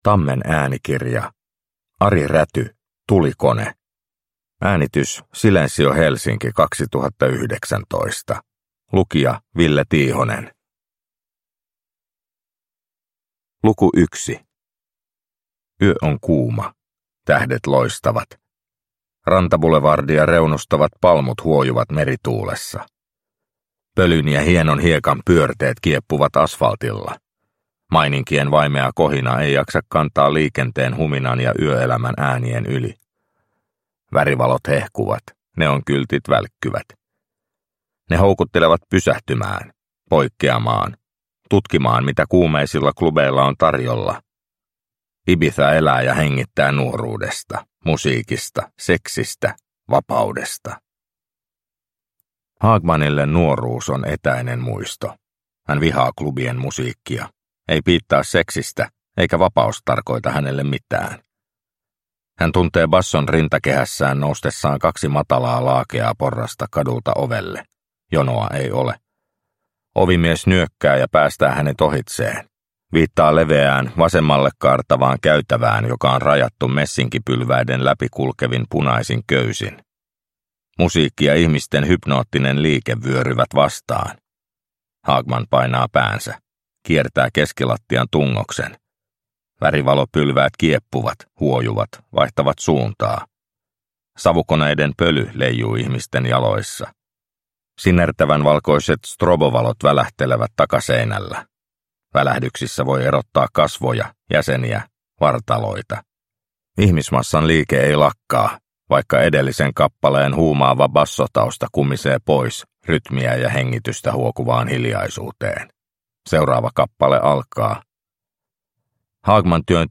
Tulikone – Ljudbok – Laddas ner